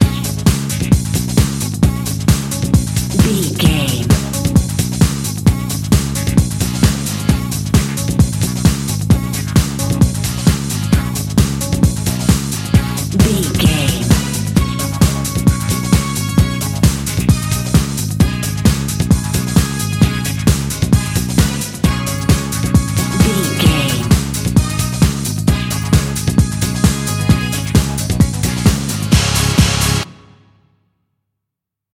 Uplifting
Ionian/Major
D
drum machine
synthesiser
bass guitar
Eurodance